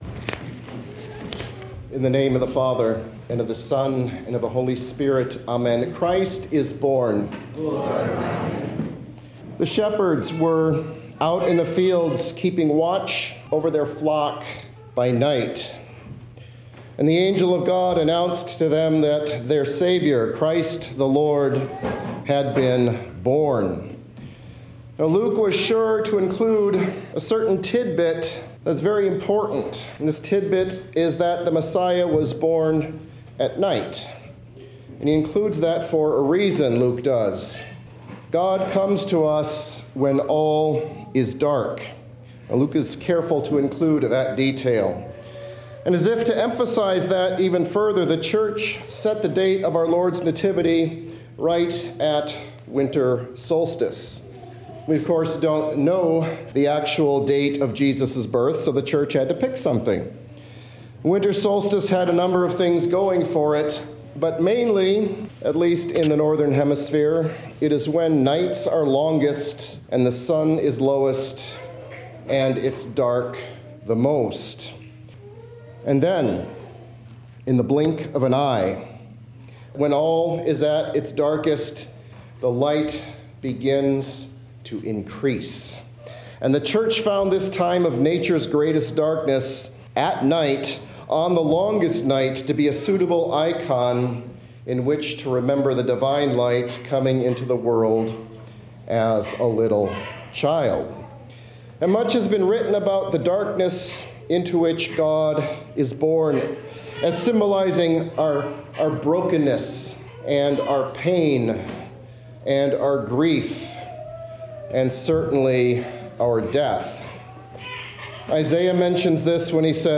Sermons 2024